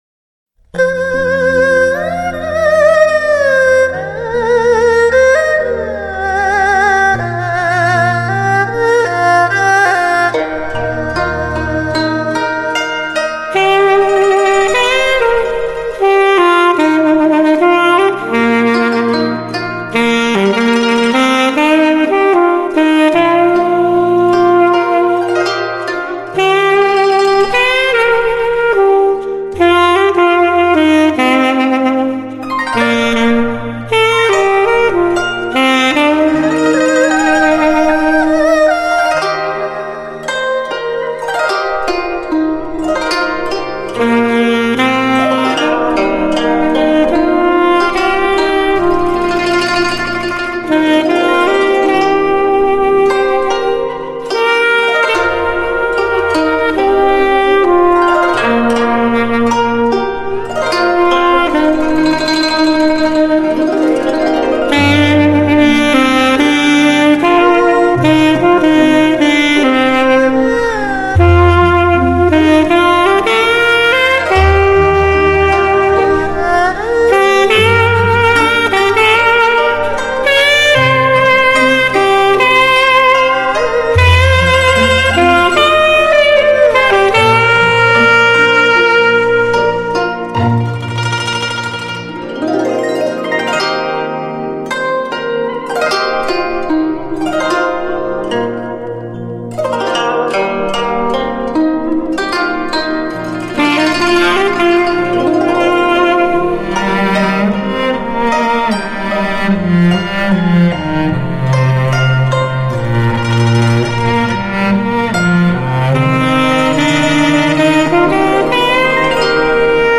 专辑格式：DTS-CD-5.1声道
世界第一品牌的萨克斯“SELMER萨尔曼”与古筝中西合壁
300平方米殿堂级录音室，英国“DDA-D系列”混音合成